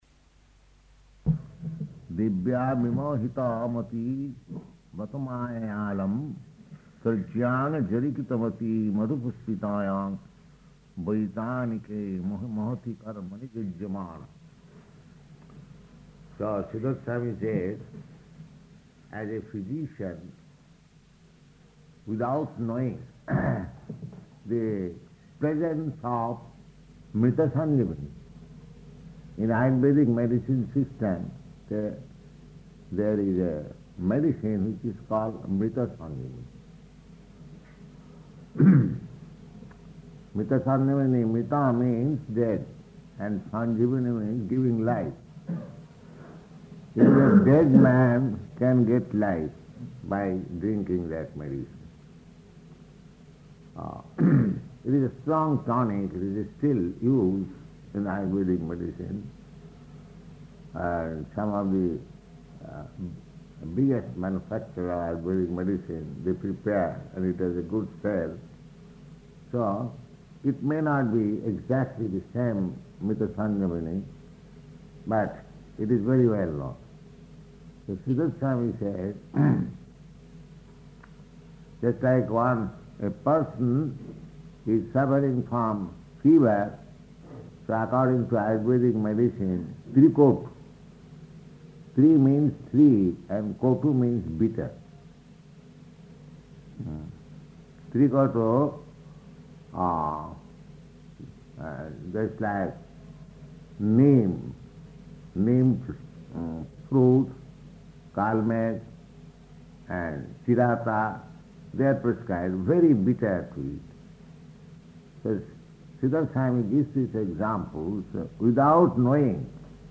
Location: Gorakphur